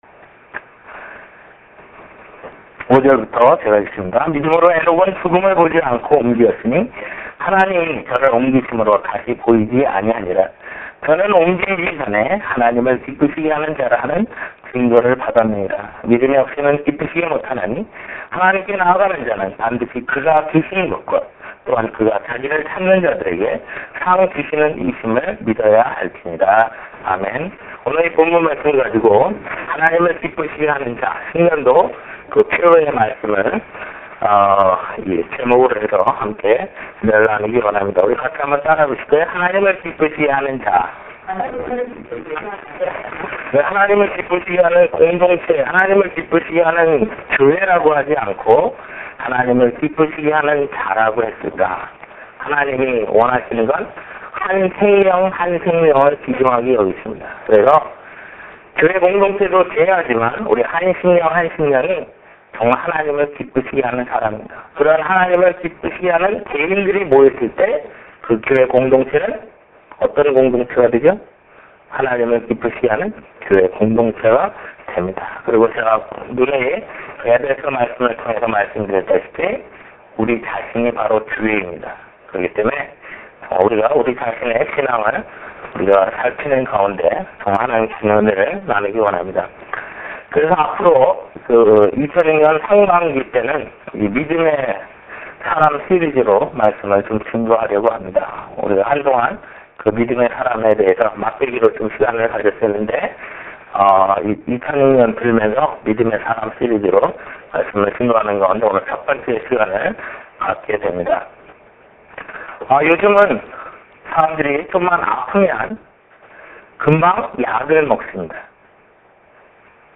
빅토빌예수마음교회 주일설교파일 - 주일설교 1월 1일